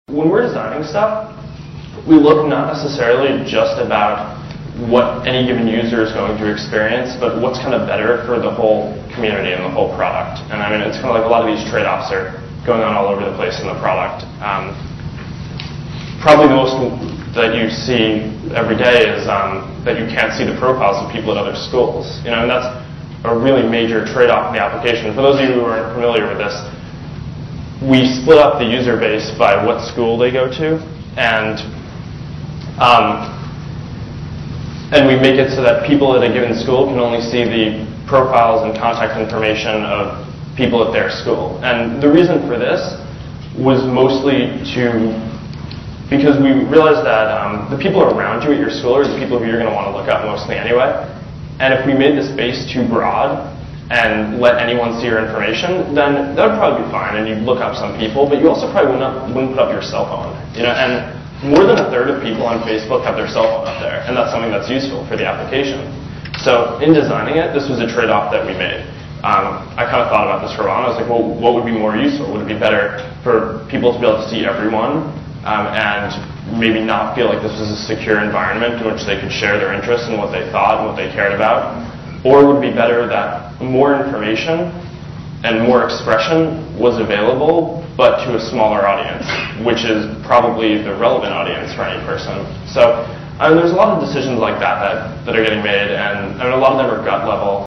财富精英励志演讲95:确定你的目标(1) 听力文件下载—在线英语听力室